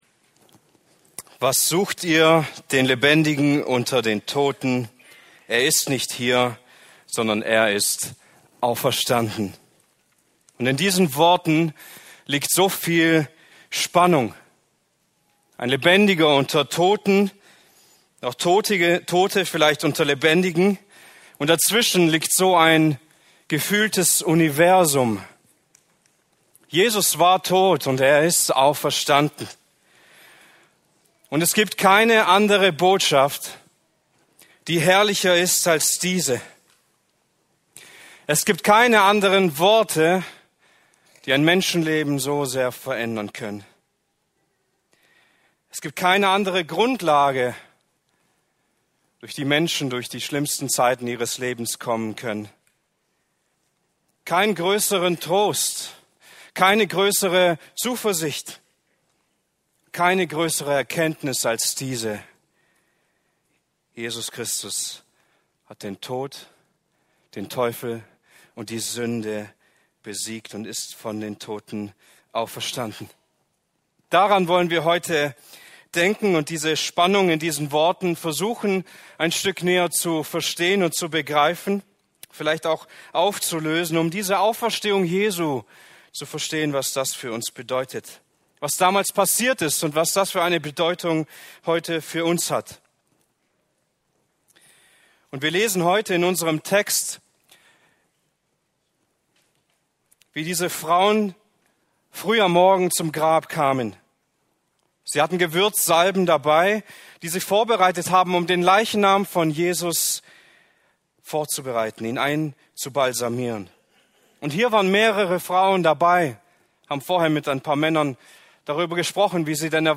Das Leben lässt sich nicht begraben ~ Evangelische Freikirche Böbingen | Predigten Podcast
Auf dieser Plattform werden regelmäßig Predigten und Themen aus unseren Gottesdienste...